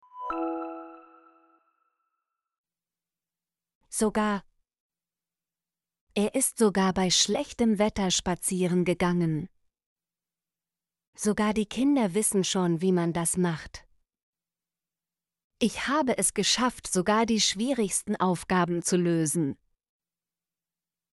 sogar - Example Sentences & Pronunciation, German Frequency List